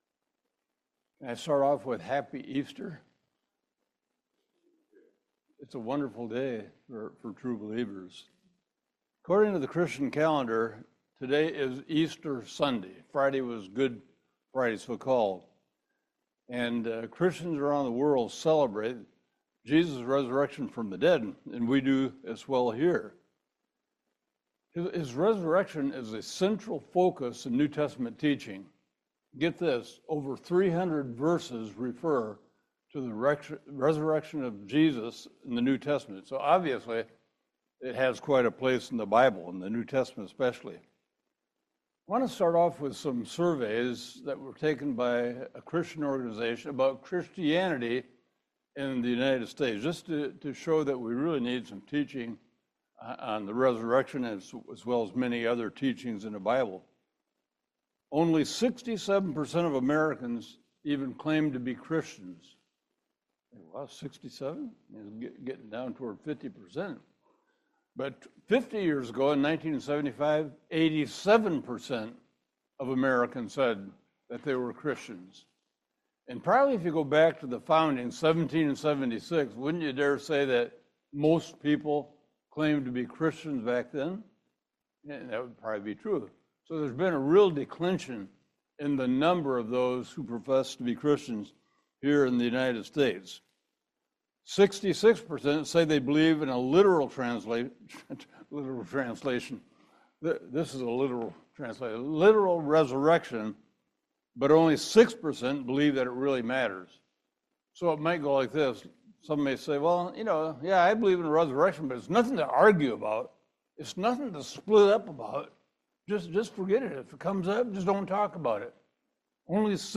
1 Corinthians 15 Service Type: Family Bible Hour The gospel culminates in Jesus Christ’s resurrection from the dead.